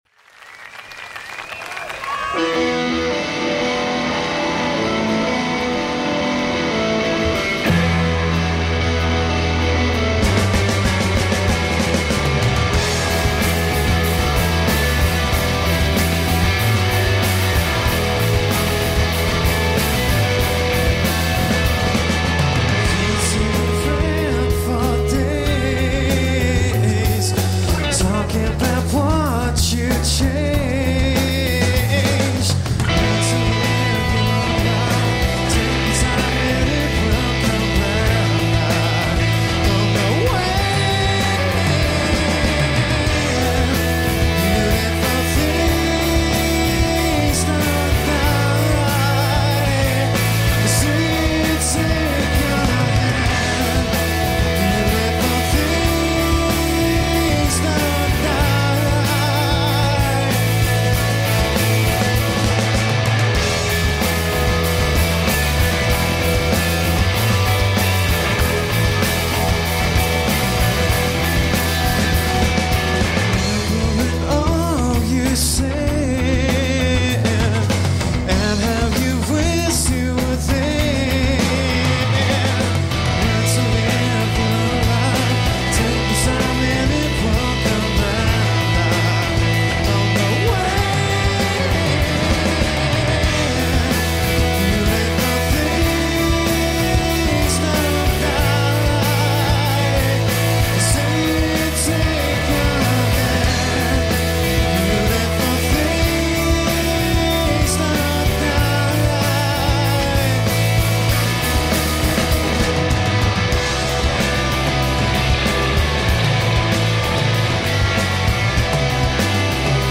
in concert at Glastonbury 2002